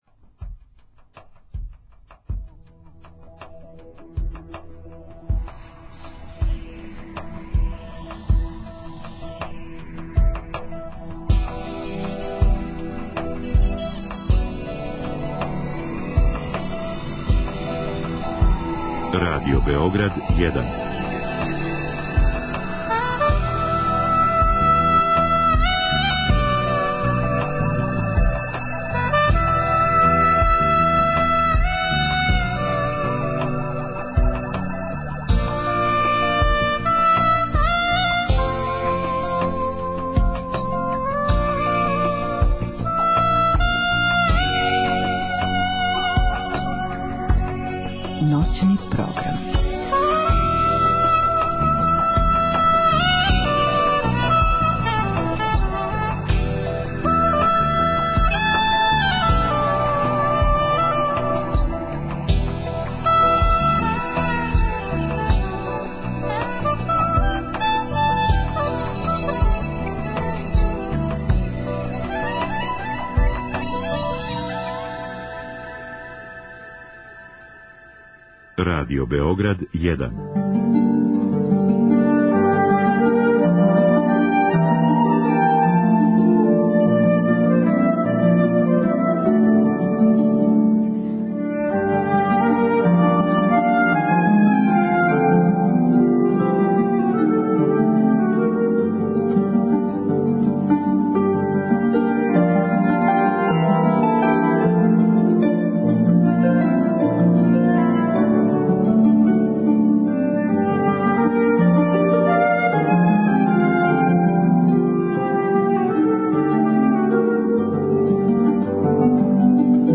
Током емисије чућемо раговоре које смо водили са организаторима и учесницима Мокрањчевих дана као и снимке са концерата одржаних током ове еминентне манифестације.
Емисију емитујемо уживо из студија у Неготину.